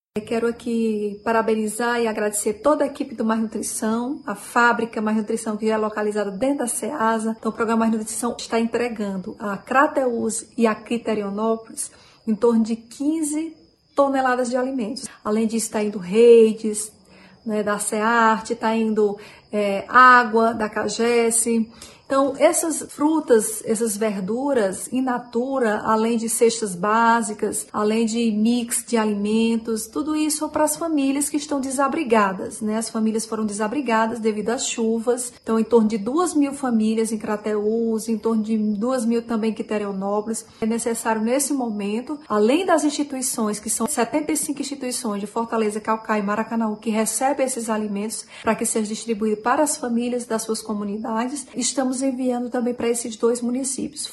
A primeira-dama do Ceará, Onélia Santana, destaca a importância da iniciativa para atender as famílias desabrigadas em decorrência das fortes chuvas.